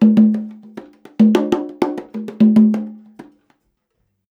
100 CONGAS17.wav